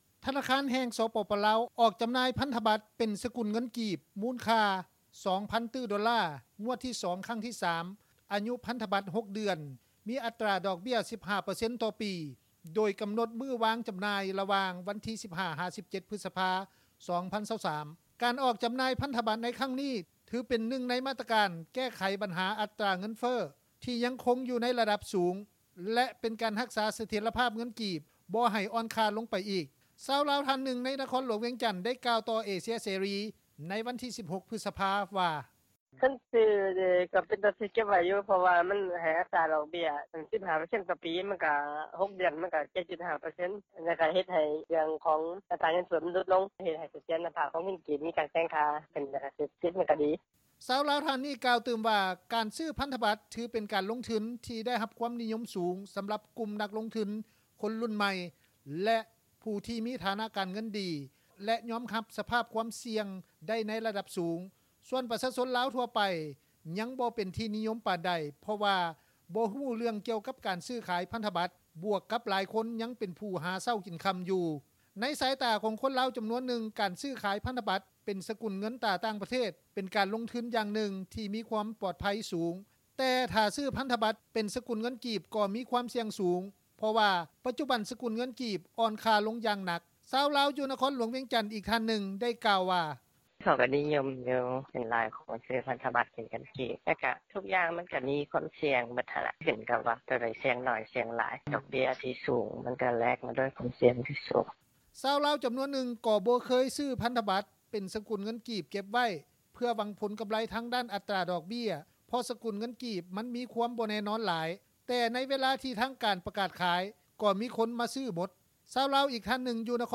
ຊາວລາວທ່ານນຶ່ງ ໃນນະຄອນຫຼວງວຽງຈັນ ກ່າວຕໍ່ ວິທຍຸ ເອເຊັຽ ເສຣີ ໃນວັນທີ 16 ພຶສພາ ວ່າ:
ຊາວລາວ ຢູ່ນະຄອນຫຼວງວຽງຈັນ ອີກທ່ານນຶ່ງ ໄດ້ກ່າວວ່າ:
ຊາວລາວອີກທ່ານນຶ່ງ ຢູ່ນະຄອນຫຼວງວຽງຈັນ ໄດ້ກ່າວວ່າ: